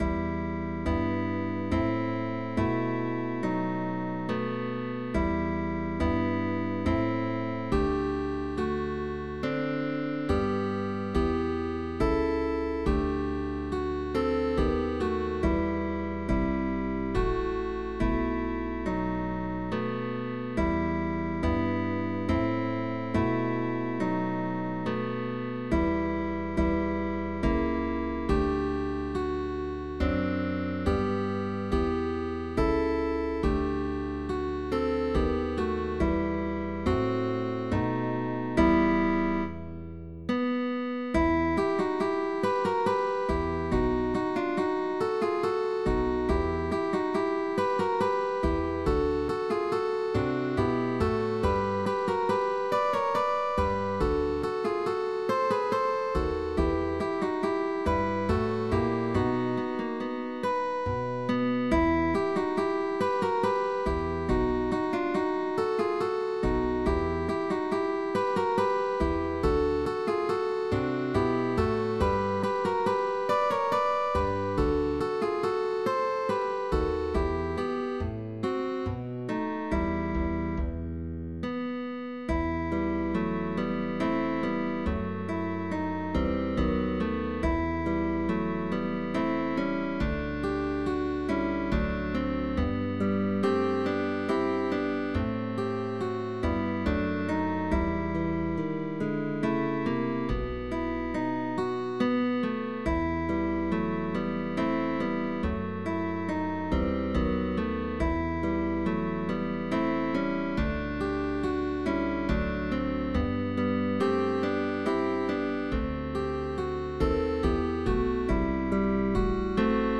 GUITAR TRIO